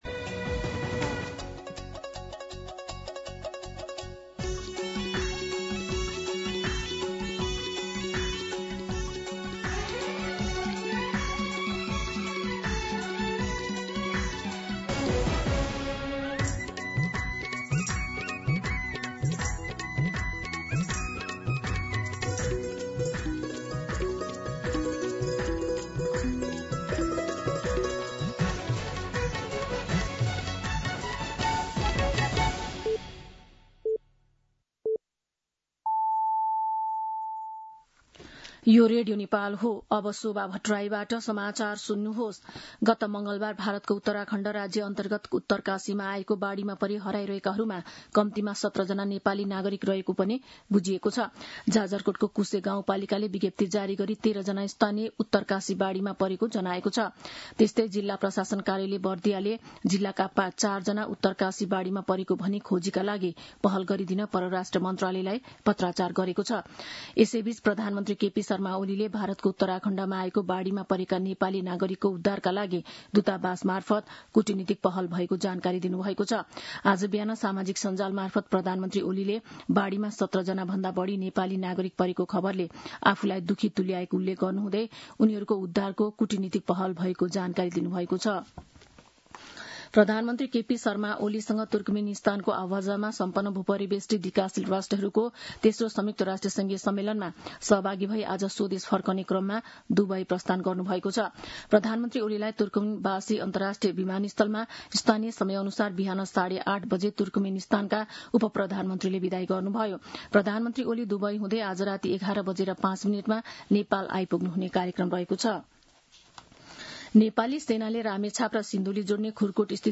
मध्यान्ह १२ बजेको नेपाली समाचार : २३ साउन , २०८२
12-pm-Nepali-News-.mp3